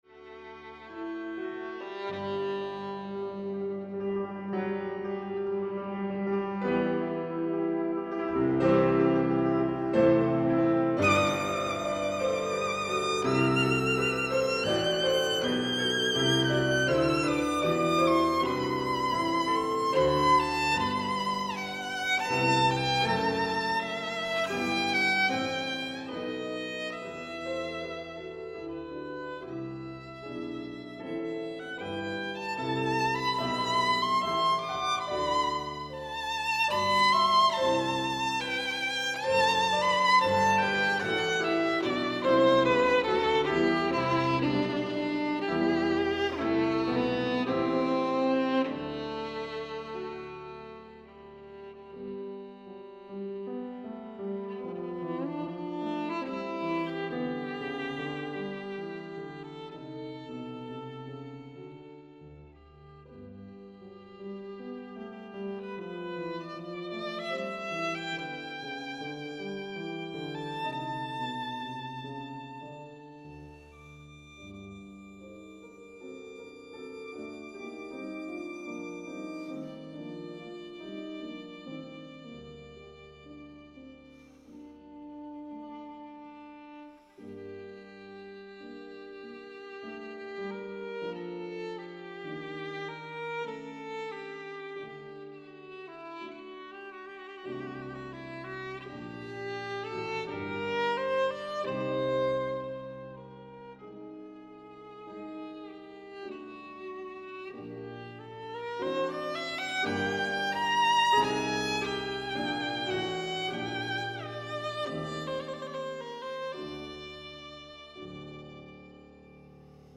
Six pieces for violin and piano P.31